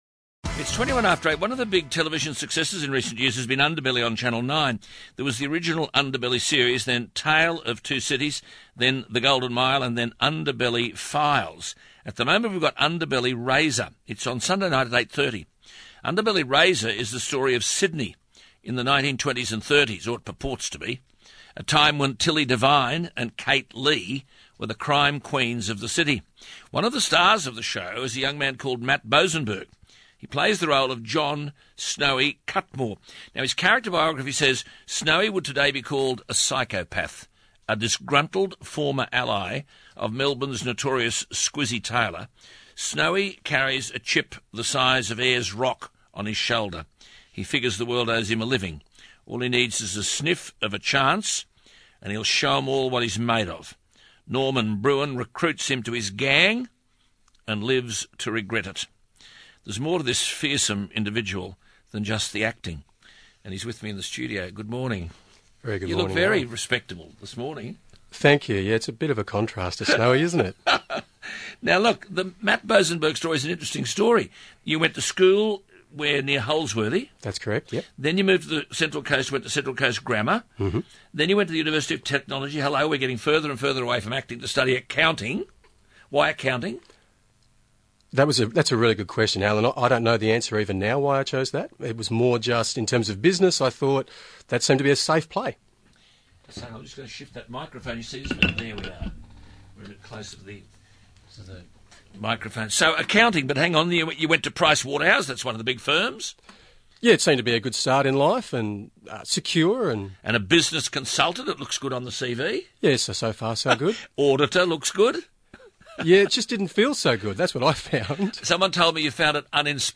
Australian talkback radio host Alan Jones